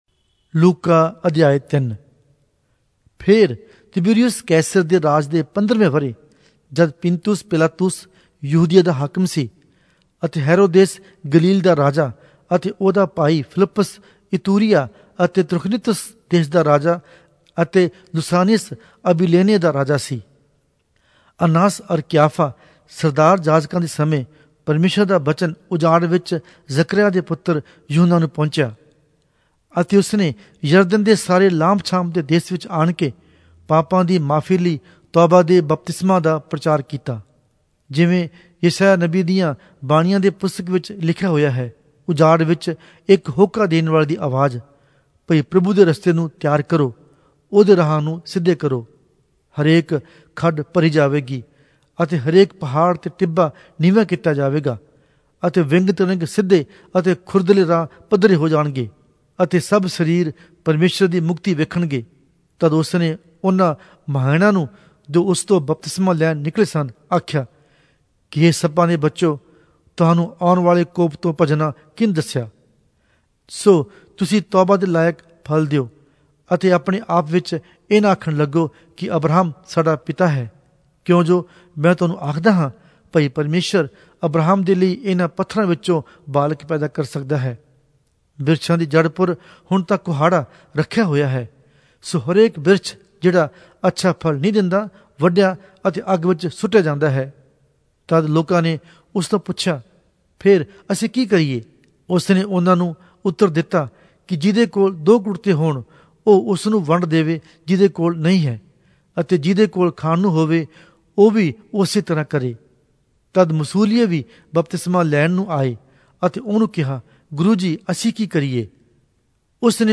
Punjabi Audio Bible - Luke 20 in Tev bible version